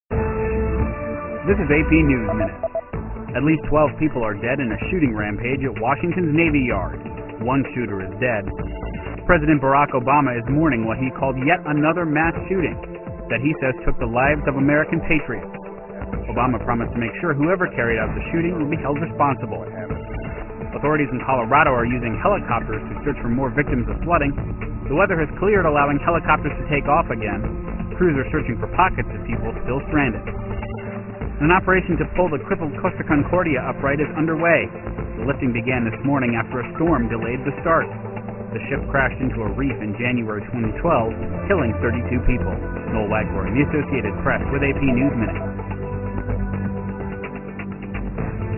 在线英语听力室美联社新闻一分钟 AP 2013-09-22的听力文件下载,美联社新闻一分钟2013,英语听力,英语新闻,英语MP3 由美联社编辑的一分钟国际电视新闻，报道每天发生的重大国际事件。电视新闻片长一分钟，一般包括五个小段，简明扼要，语言规范，便于大家快速了解世界大事。